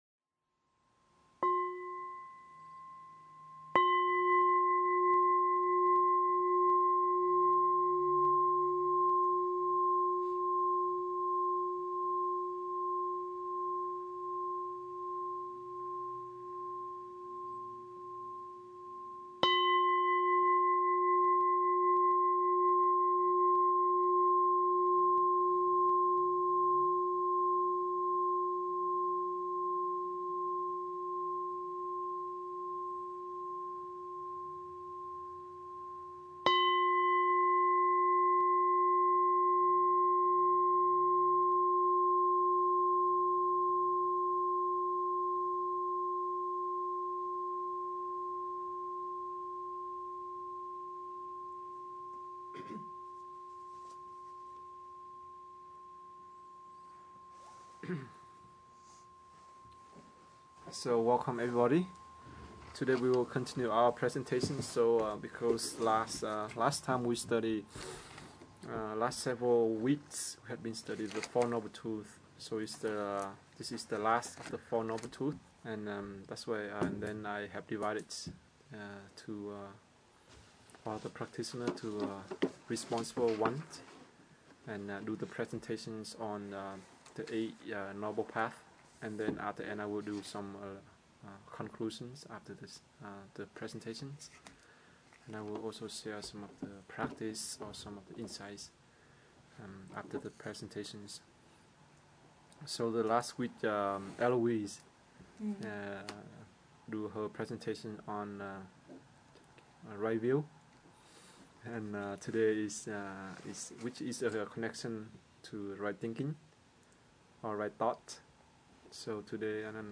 Dharma Talk 9/16/2008